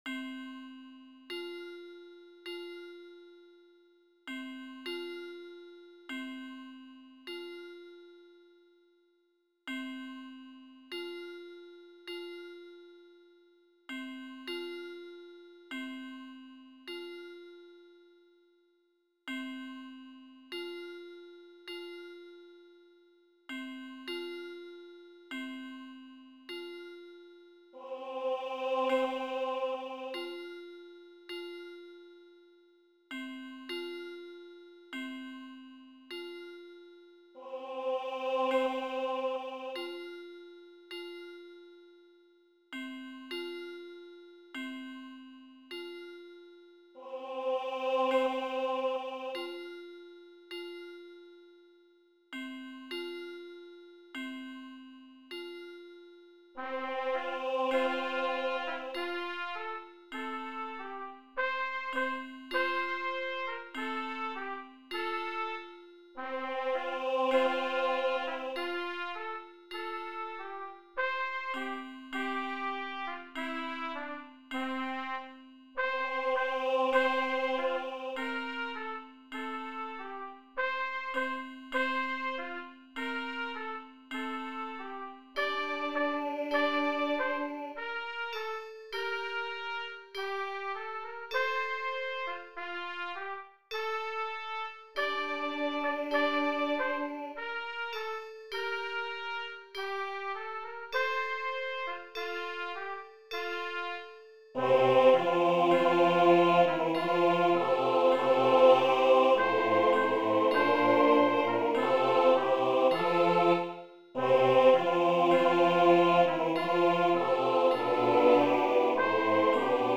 Brass Quintet, Hand Bells/Hand Chimes, Organ/Organ Accompaniment, Percussion, SATB, Trumpet
Voicing/Instrumentation: SATB , Brass Quintet , Hand Bells/Hand Chimes , Organ/Organ Accompaniment , Percussion , Trumpet We also have other 38 arrangements of " Hark!